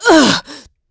Isolet-Vox_Damage_02.wav